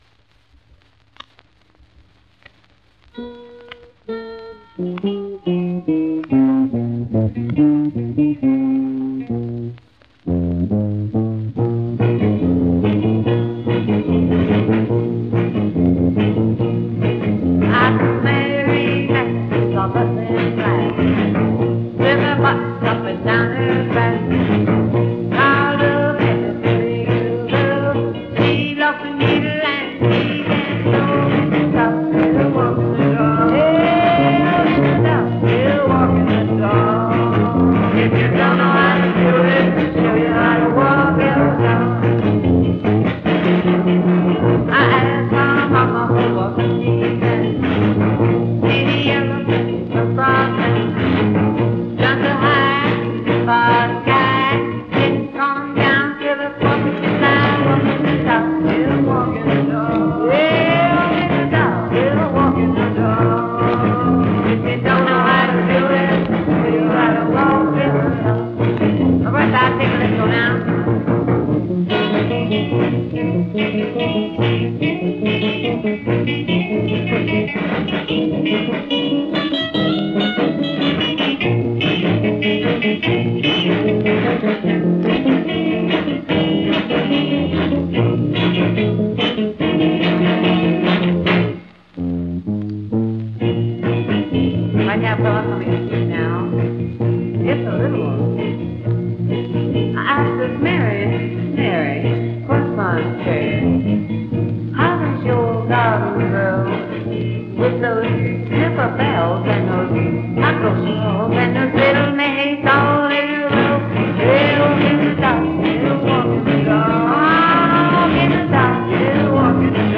drums
bass